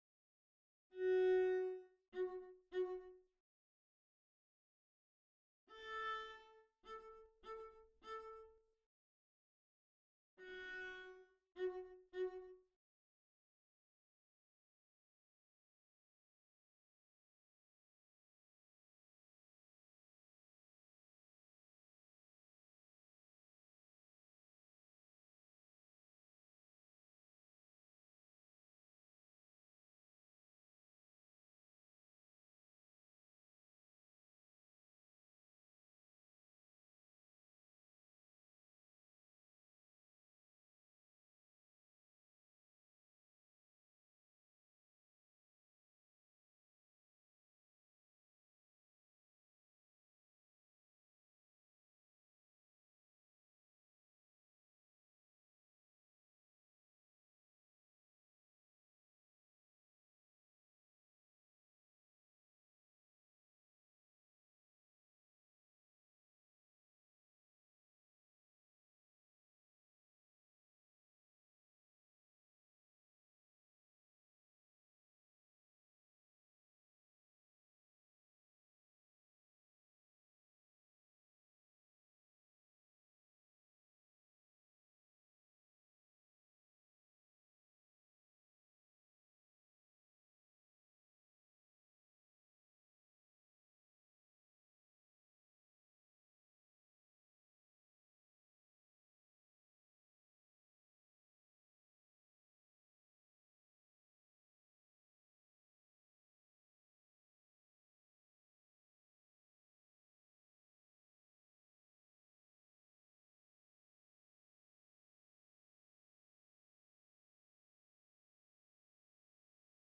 2. Viola (Viola/Arco)
Schubert-Trout-Quintet-06-Viola_1.mp3